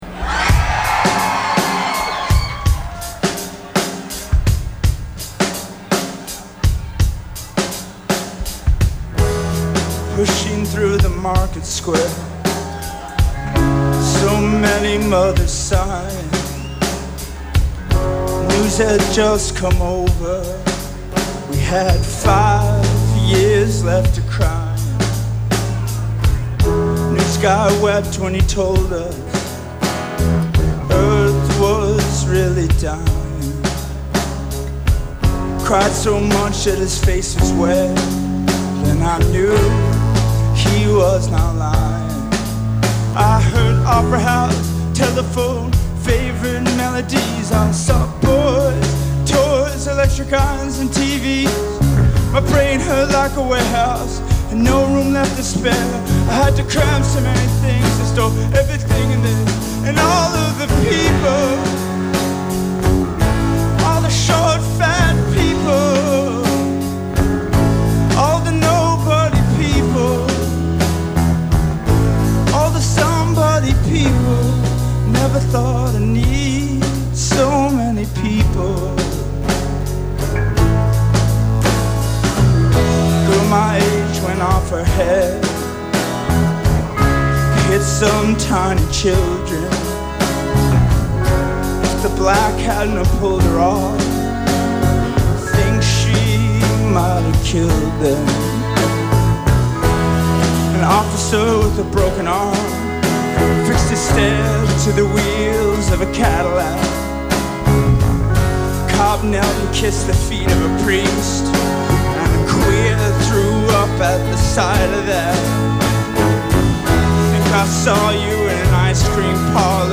cover, live @ 1st Ave in Minneapolis